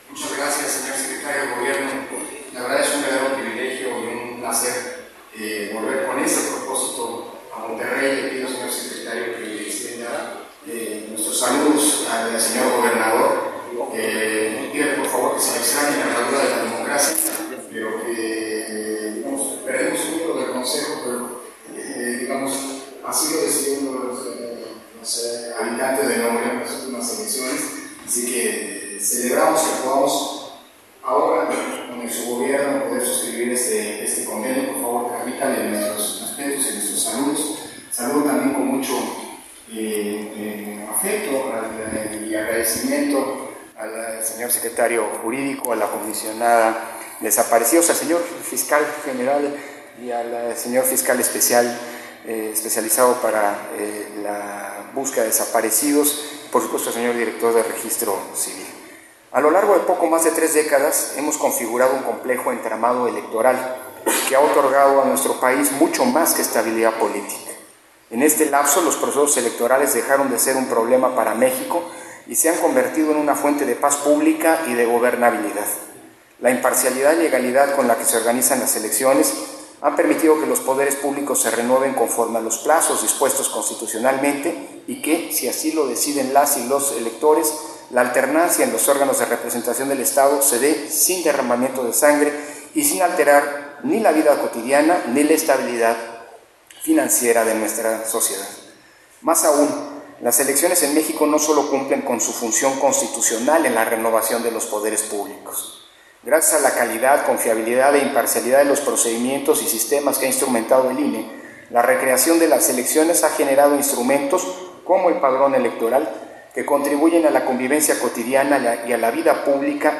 Intervención de Lorenzo Córdova, en la firma de Convenio de colaboración INE-Gobierno de Nuevo León